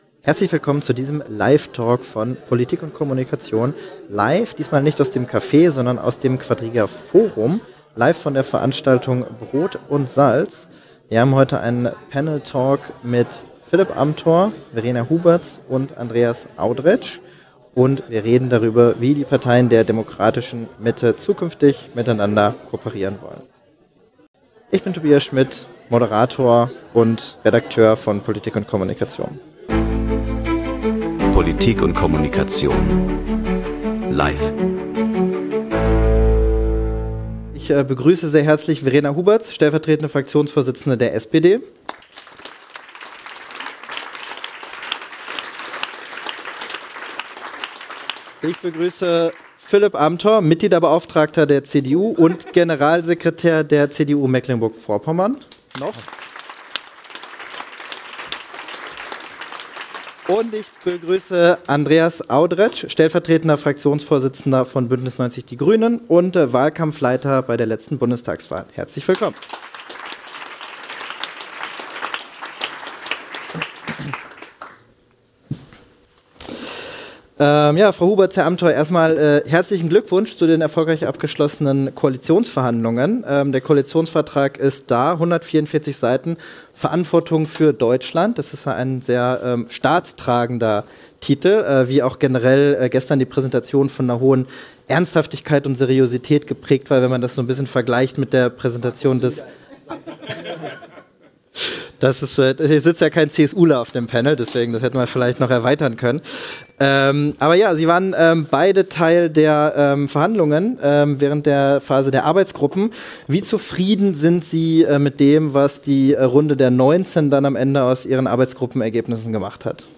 Anlass war Brot&Salz, das traditionelle Homecoming-Event für neugewählte Bundestagsabgeordnete von politik&kommunikation und Quadriga.